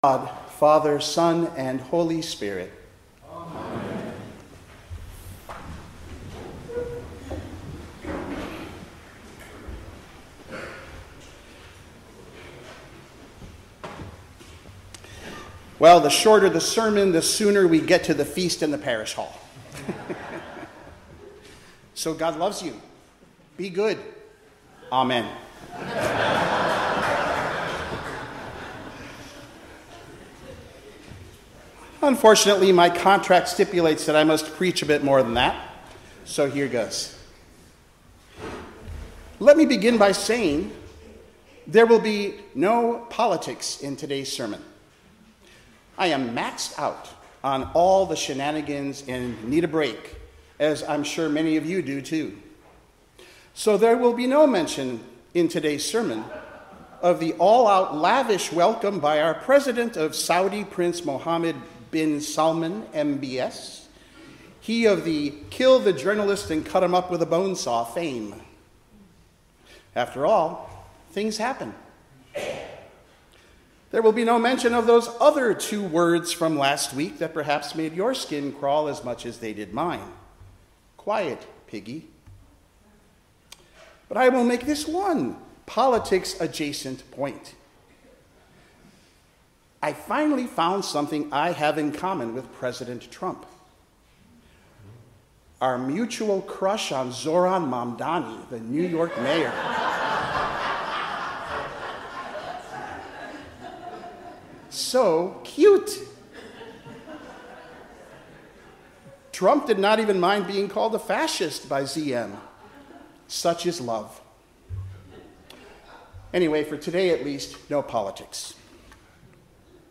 Passage: Jeremiah 23:1-6, Psalm 46, Colossians 1:11–20, Luke 23:33–43 Service Type: 10:00 am Service